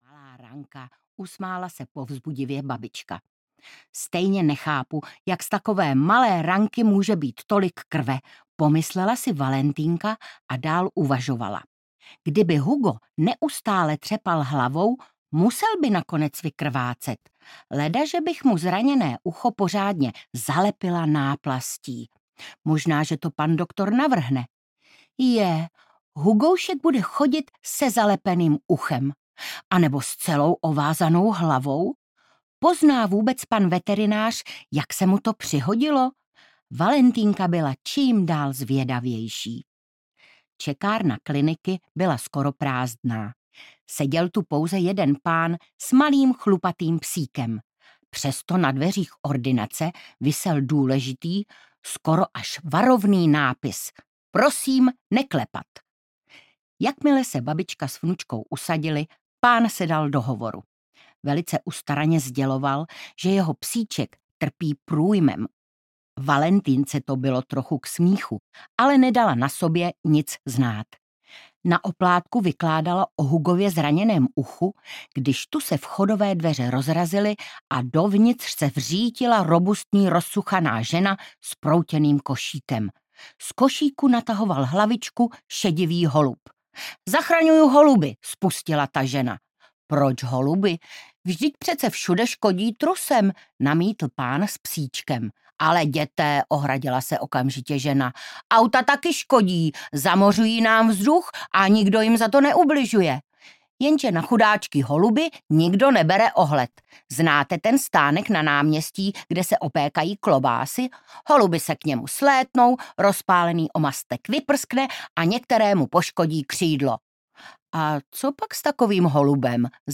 Valentýnka a veterinární ordinace audiokniha
Ukázka z knihy
• InterpretIvana Andrlová